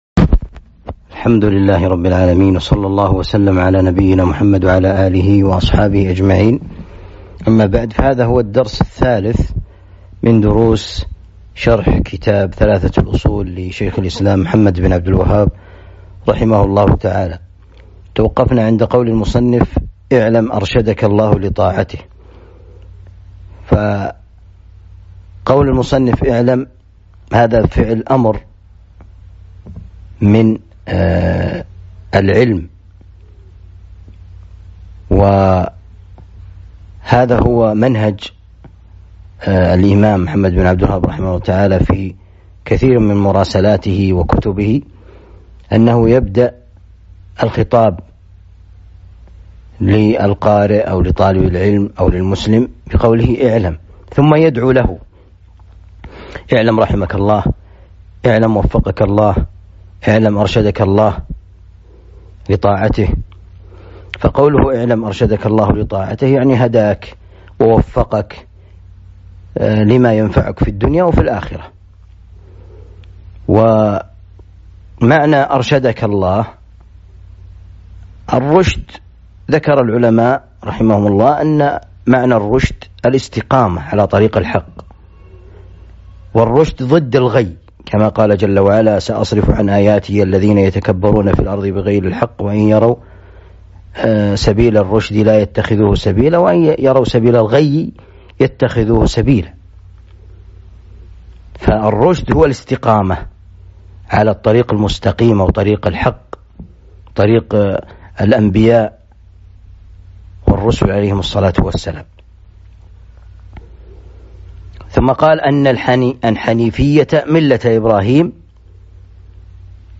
الدرس الثالث من شرح ثلاثة الأصول